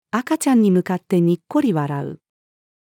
赤ちゃんに向かってにっこり笑う。-female.mp3